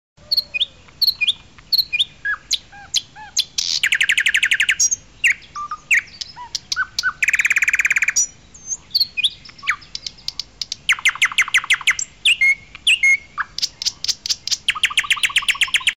Categoria Animali